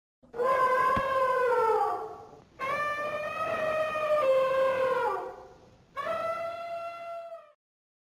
AFRICAN ELEPHANT
Click below to listen to the noise this animal makes
Elephant.mp3